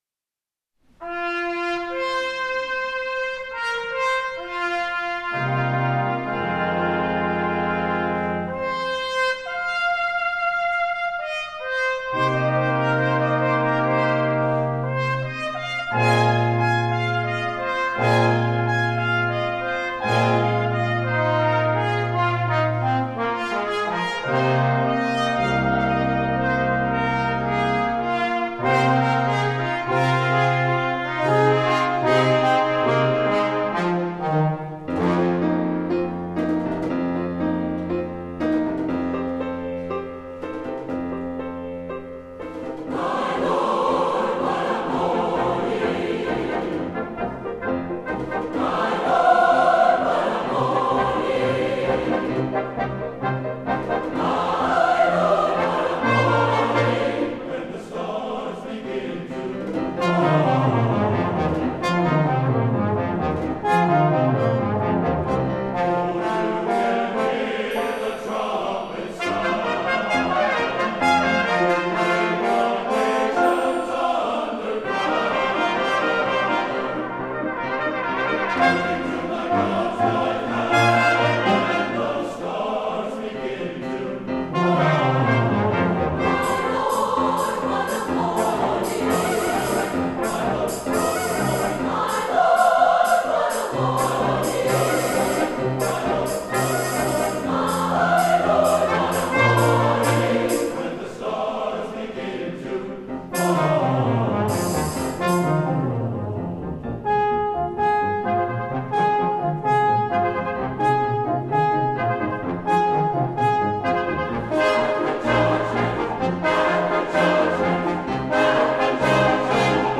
for SATB Chorus, Brass Quintet, Percussion, and Piano (2005)